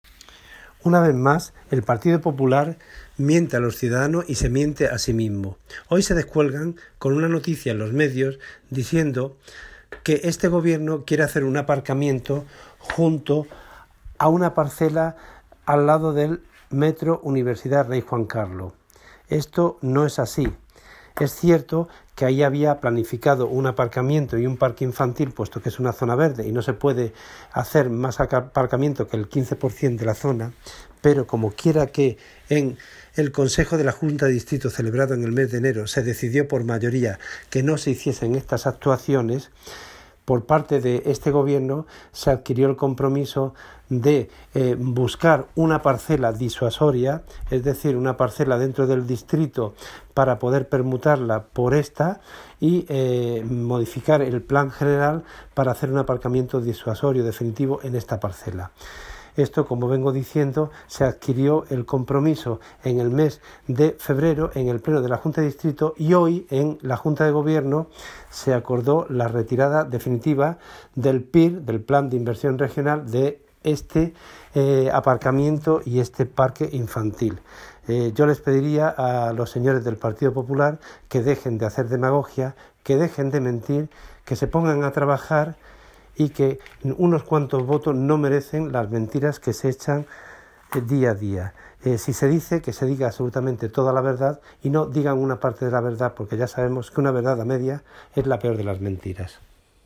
Audio - Agustín Martín (Concejal de Deportes, Obras, Infraestructuras y Mantenimiento de Vías Públicas)